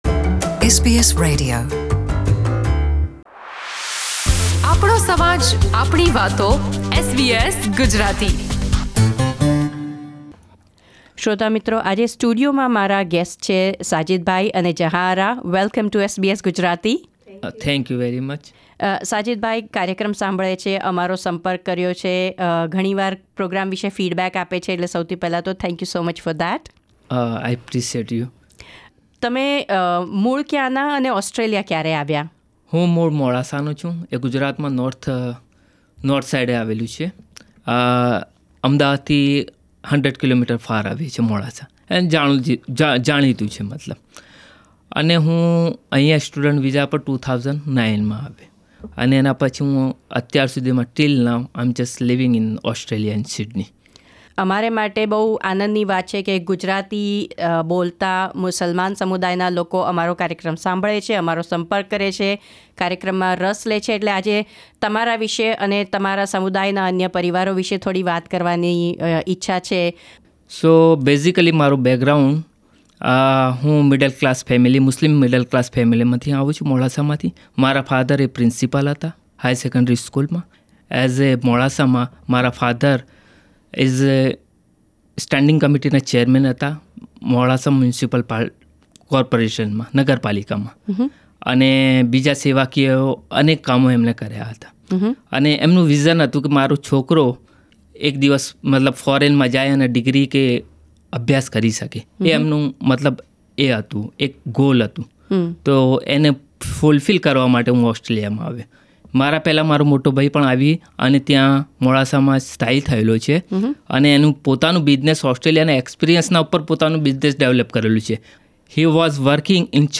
પ્રસ્તુત છે SBS Gujarati સાથે તેમણે કરેલી વાતચીત...